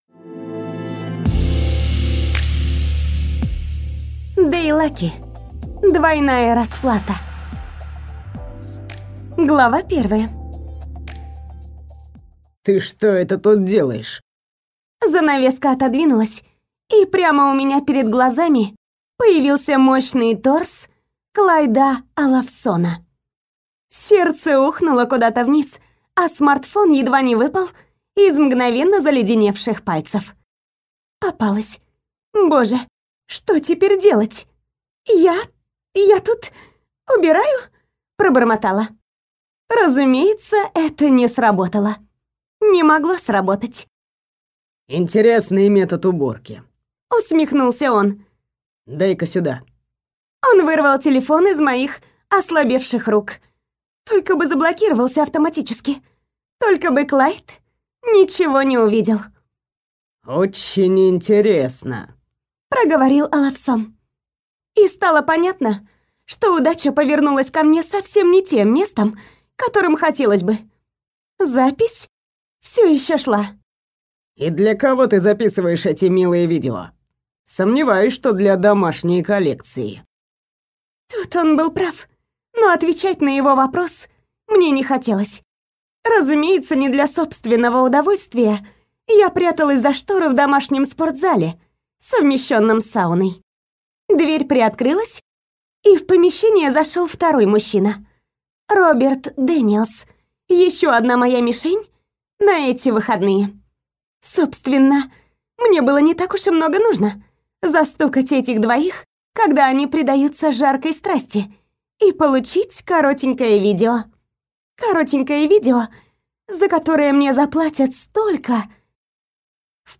Аудиокнига Двойная расплата | Библиотека аудиокниг